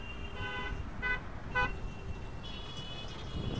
Here we host our database "IUEC (IIITD Urban Environment Context) database" which contains distress (scream and cry sounds) and sounds of 6 environmental contexts collected from mobile phones, movies and Internet.
Outdoor sounds     metro.wav
road_horn.wav